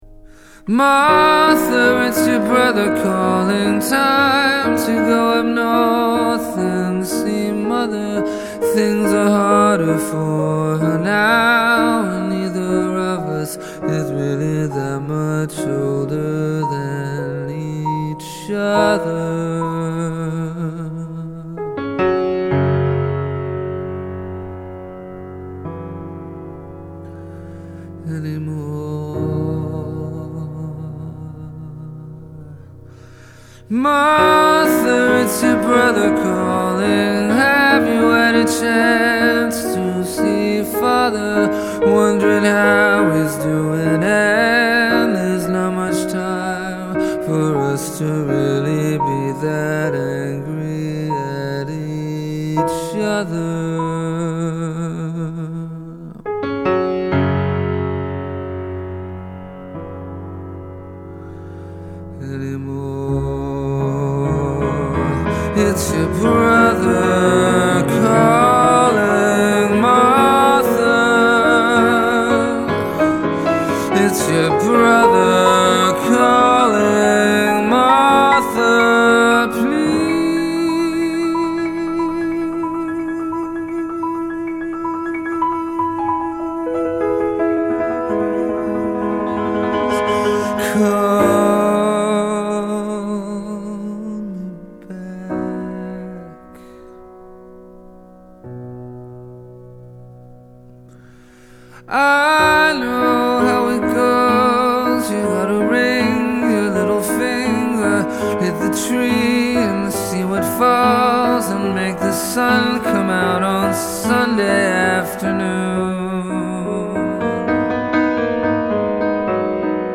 It’s a touching and contemplative work